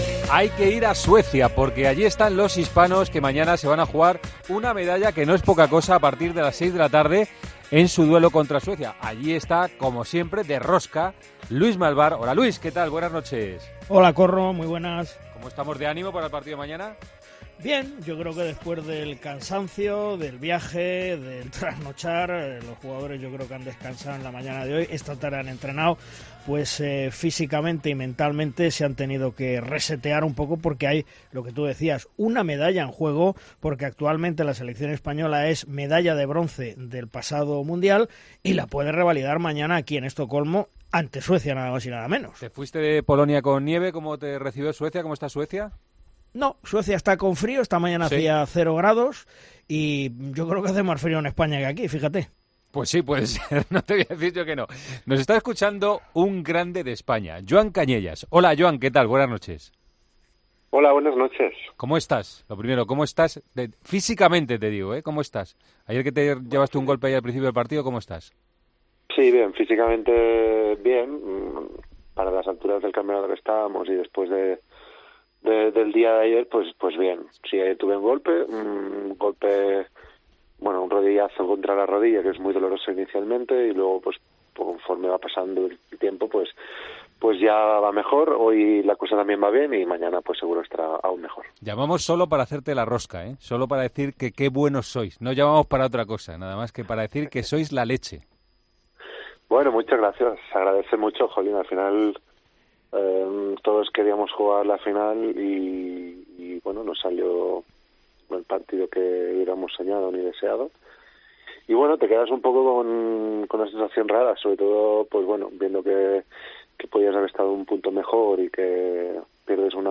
AUDIO: En Tiempo de Juego hablamos con el jugador de 'Los Hispanos' ante de su partido por el tercer y cuarto puesto.